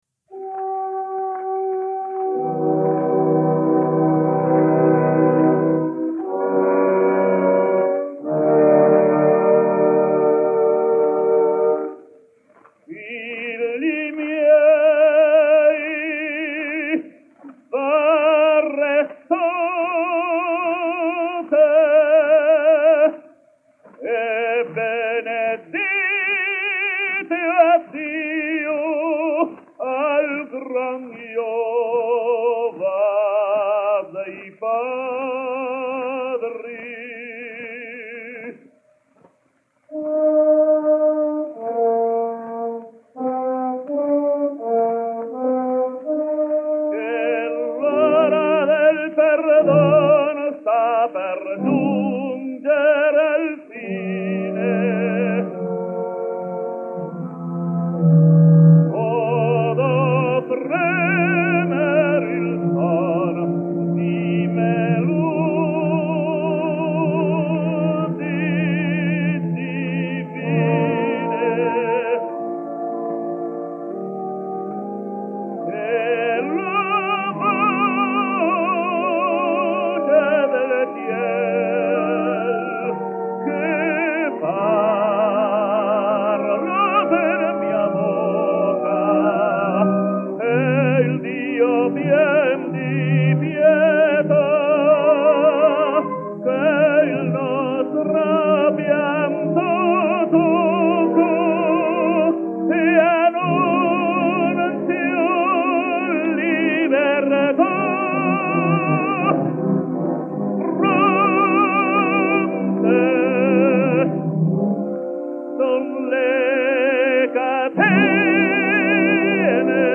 Puerto Rican Tenor.
An aria from Samson and Delilah